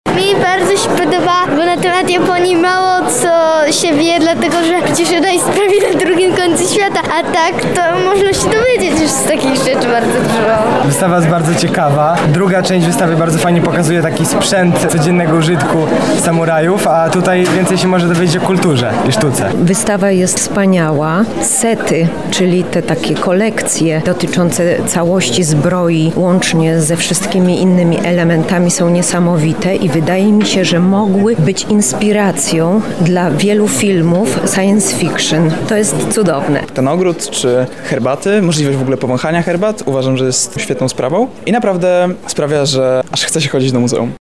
„Świat według Plant”, relacja z premiery spektaklu
uczestnicy_01-1.mp3